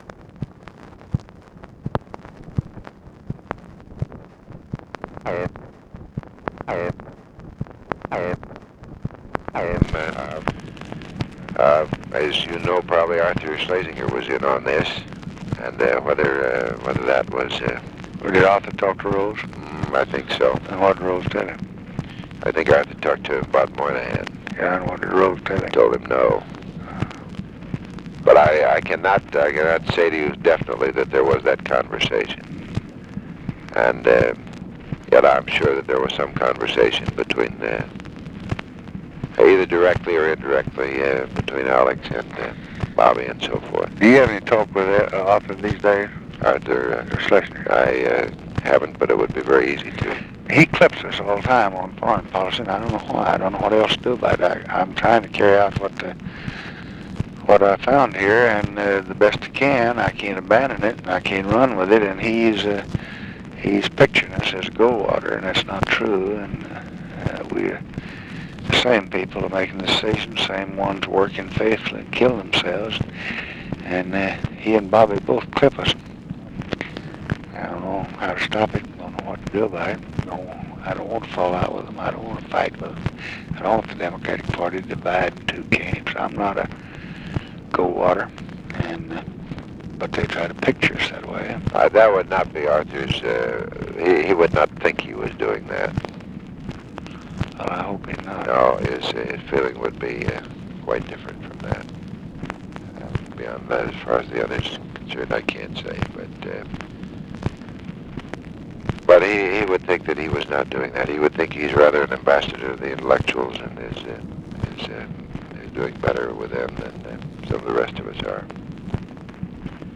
Conversation with WILLARD WIRTZ, June 24, 1965
Secret White House Tapes